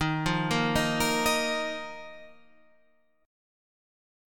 D#sus2 chord